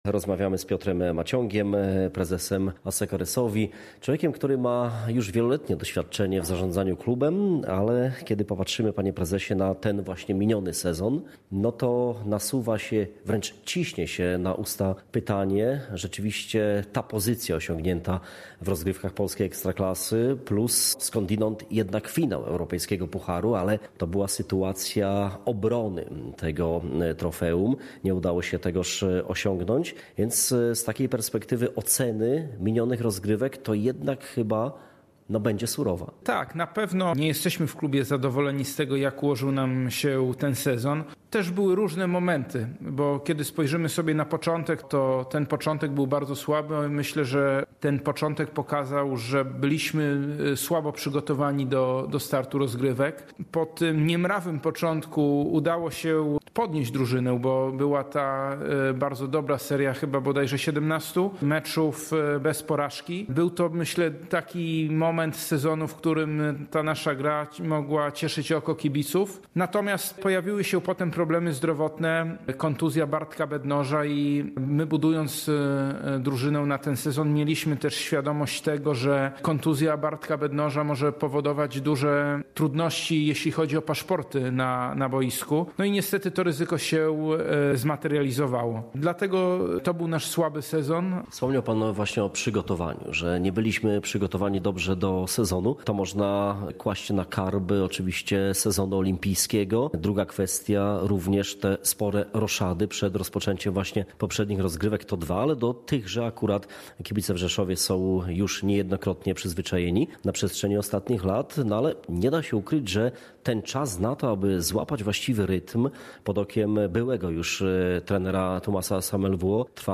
w obszernej rozmowie